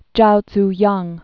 (jou dz-yäng)